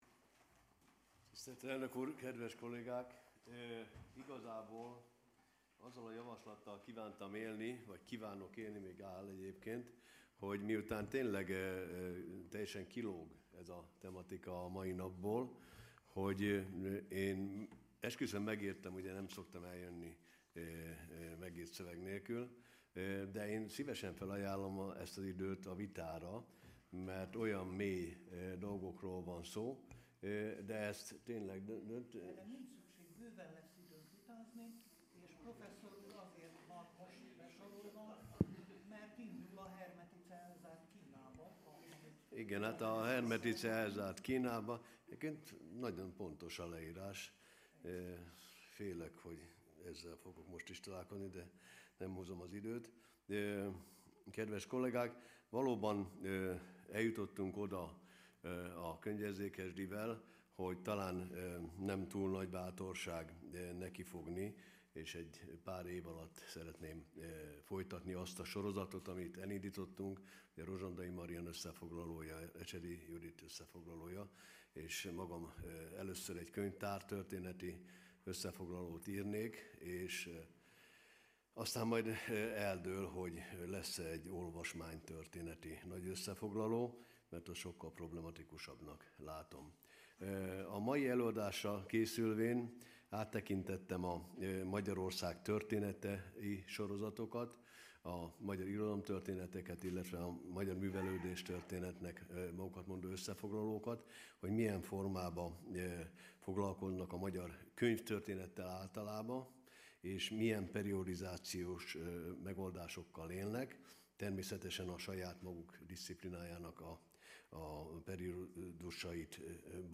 Channels Hagyományőrzés és önbecsülés. Száz éve született Tarnai Andor , Második ülés , Hagyományőrzés és önbecsülés.
(lecturer)